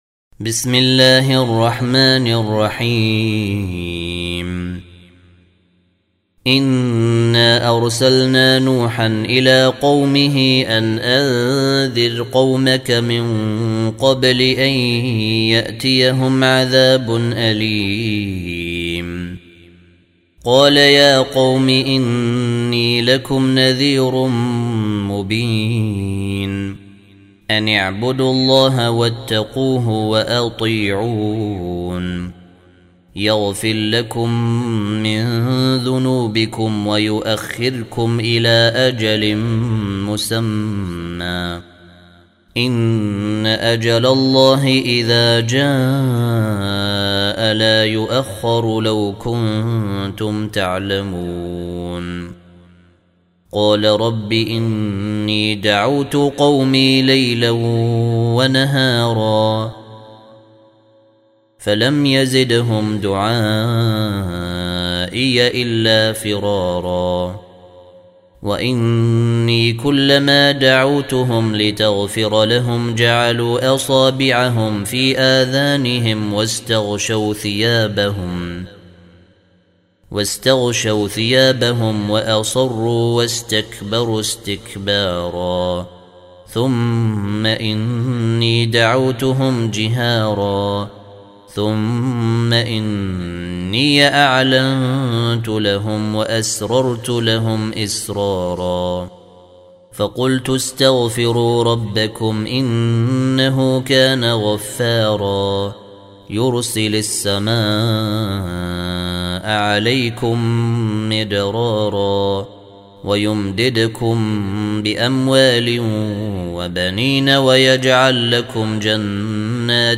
Surah Repeating تكرار السورة Download Surah حمّل السورة Reciting Murattalah Audio for 71. Surah N�h سورة نوح N.B *Surah Includes Al-Basmalah Reciters Sequents تتابع التلاوات Reciters Repeats تكرار التلاوات